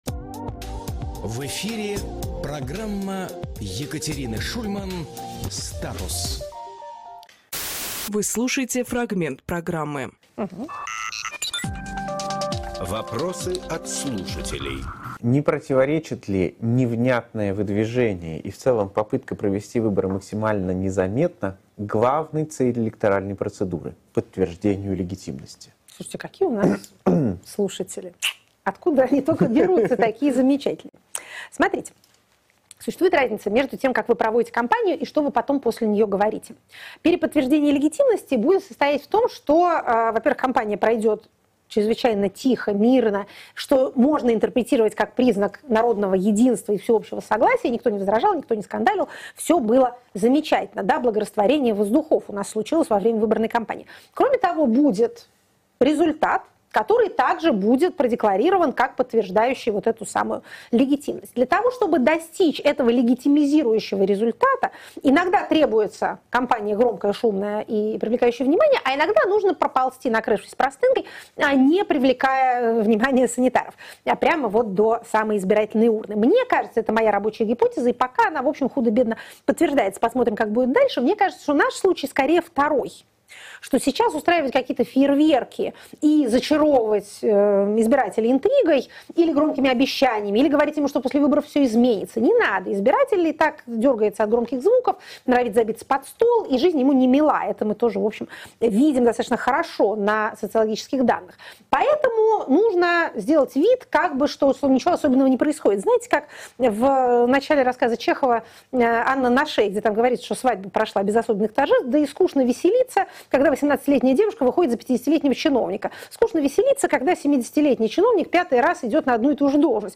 Екатерина Шульманполитолог
Фрагмент эфира от 21.12